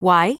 OCEFIAudio_en_LetterY.wav